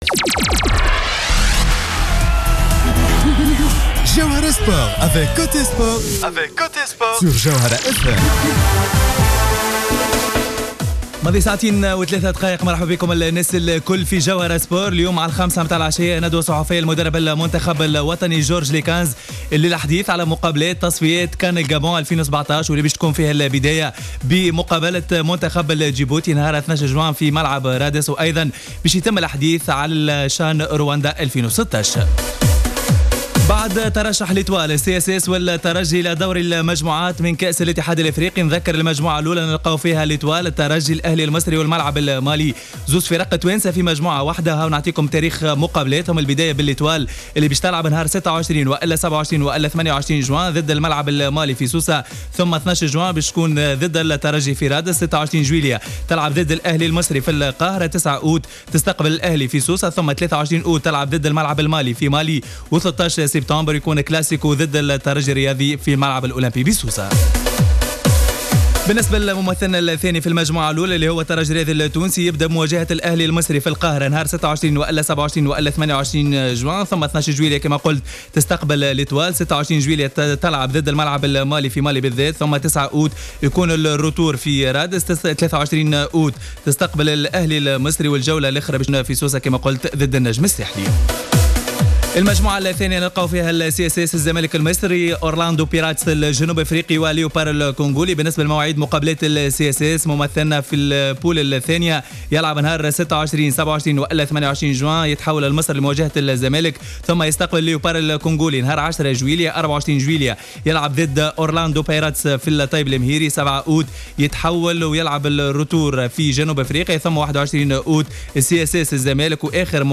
ندوة صحفية لمدرب المنتخب جورج ليكنز